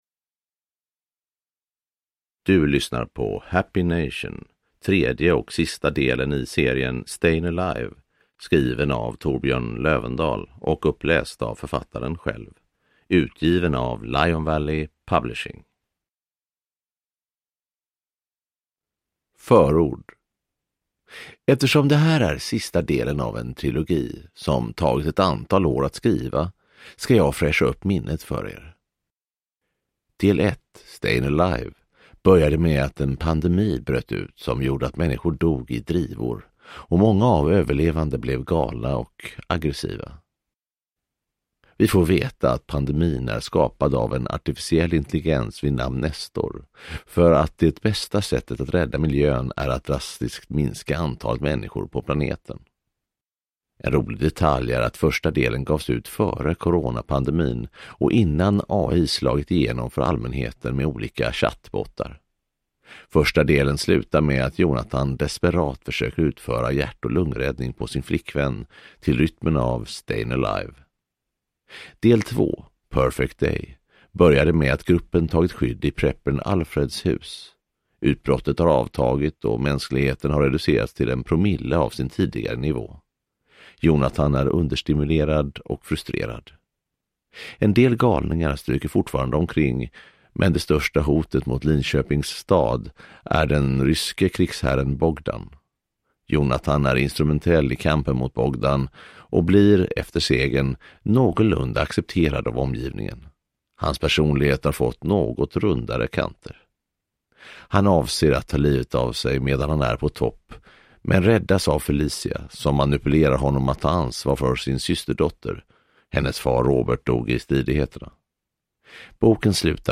Happy Nation – Ljudbok